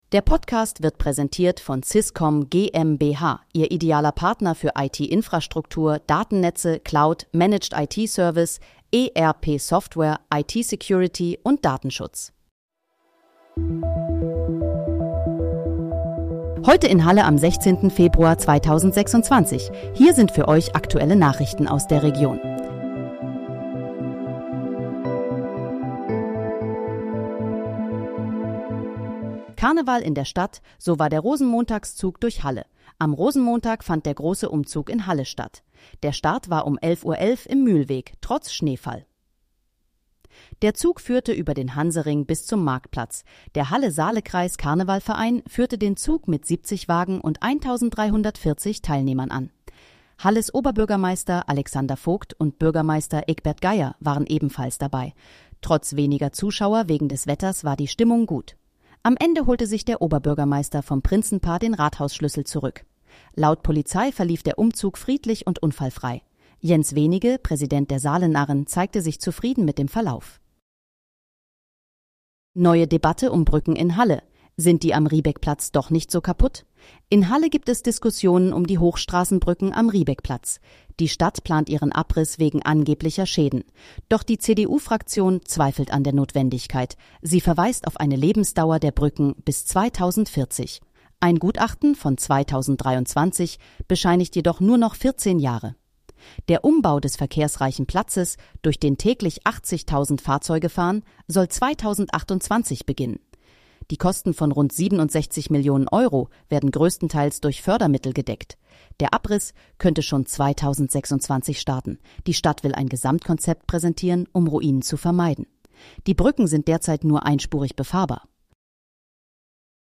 Heute in, Halle: Aktuelle Nachrichten vom 16.02.2026, erstellt mit KI-Unterstützung
Nachrichten